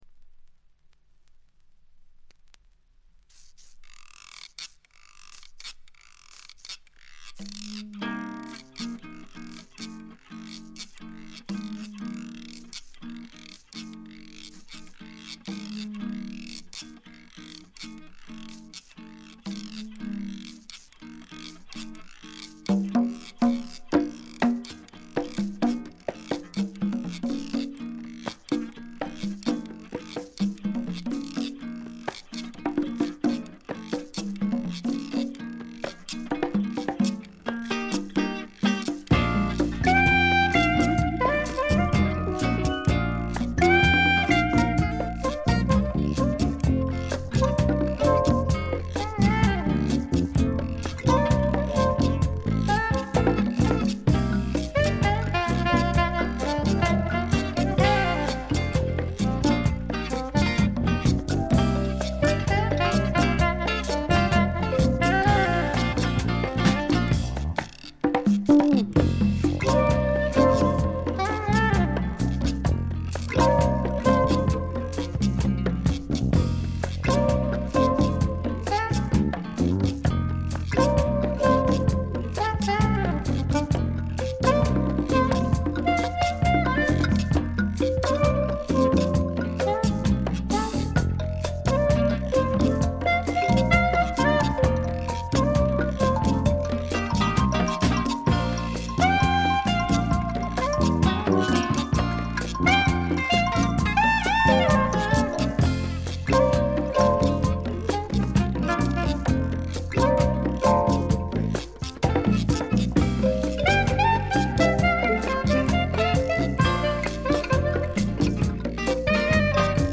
ほとんどノイズ感無し。
試聴曲は現品からの取り込み音源です。
Steel Drums, Percussion
Recorded at Conway Recorders Co., Hollywood, Aug. 1976.